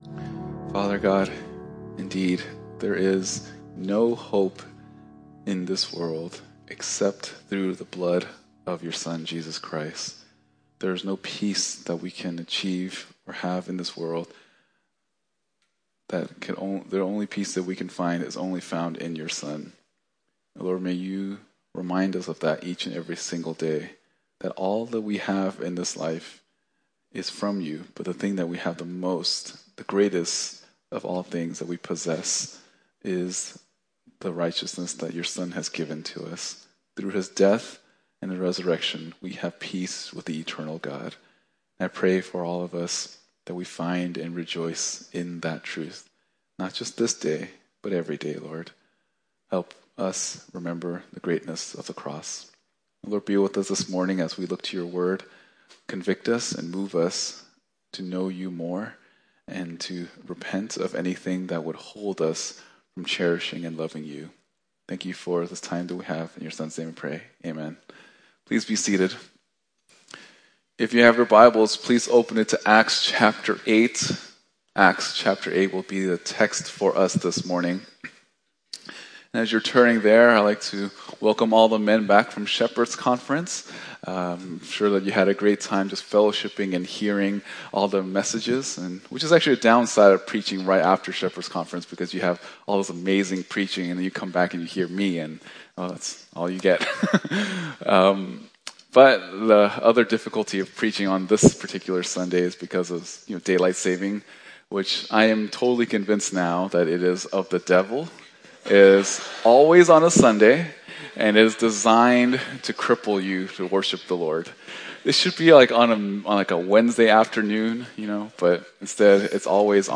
San Francisco Bible Church - Sunday Sermons